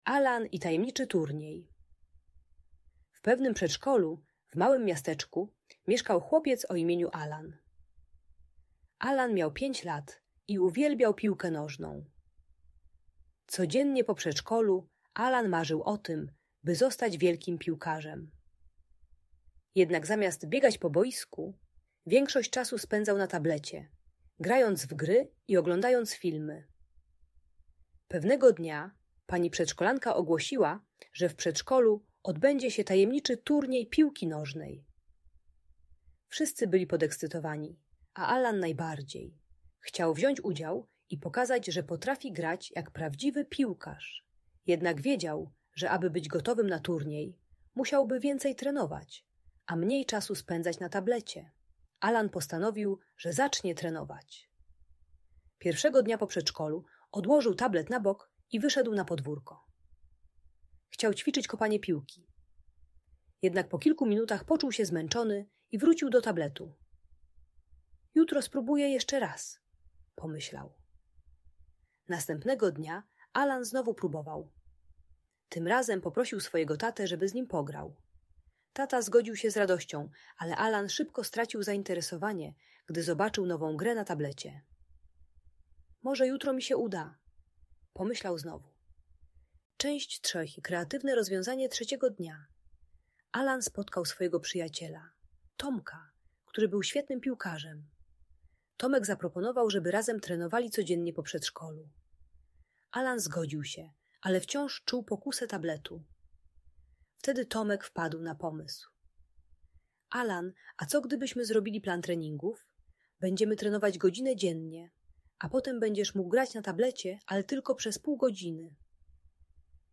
Allan i Tajemniczy Turniej - Bajki Elektronika | Audiobajka